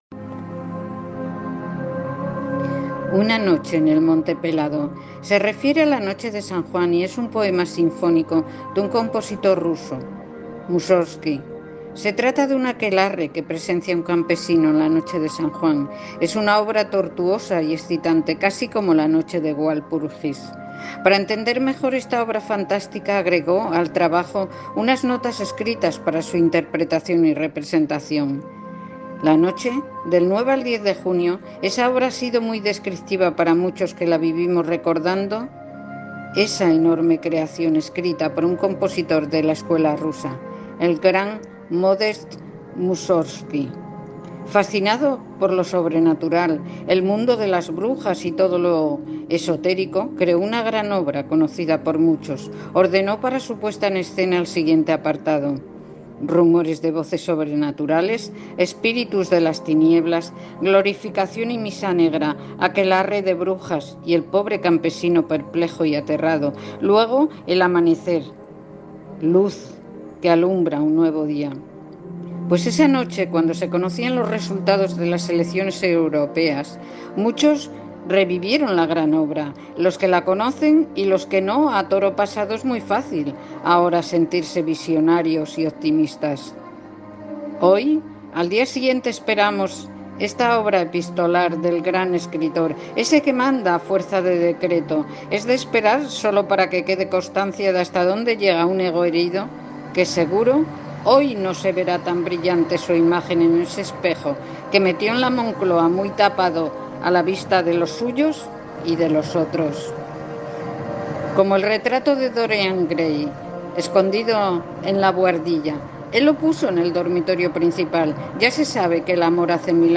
Modest Músorgski I Fuente: Dallas Symphony Orchestra
Se refiere a la noche de San Juan y es un poema sinfónico de un compositor ruso Modest Músorgski
Se trata de un aquelarre que presencia un campesino en la noche de San Juan, es una obra tortuosa y excitante, casi como La noche de Walpurgis.